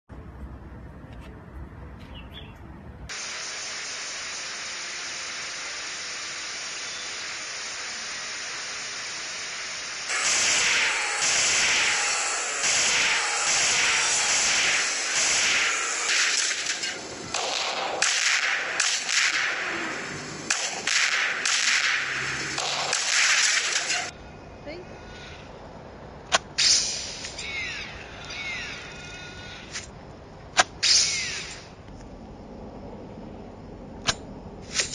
AR game gun and bow sound effects free download